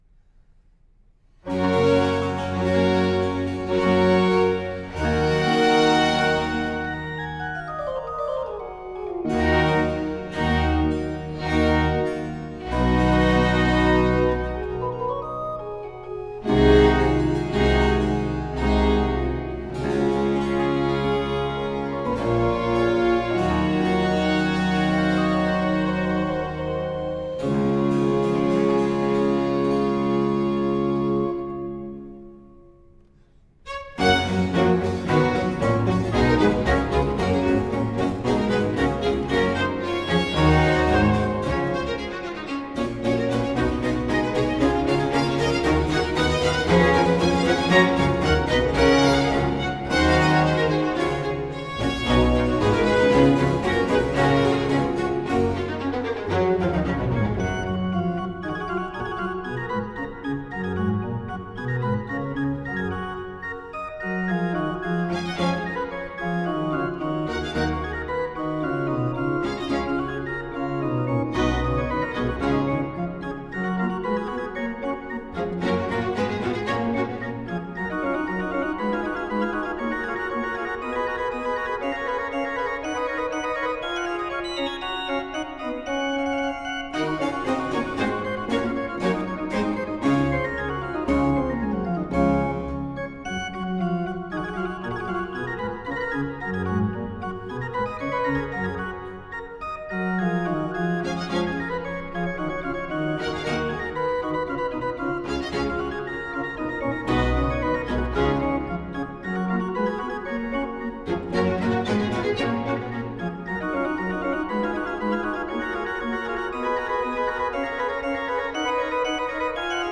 William Hayes: Organ Concerto in G major. IV. Minuetto Allegro.